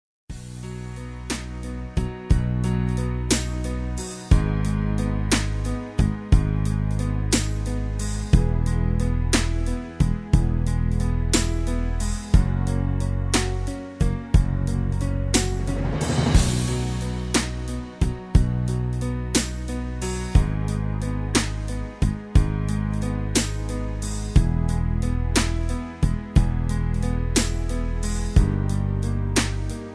karaoke , backing tracks